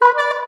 pushHornHonk.ogg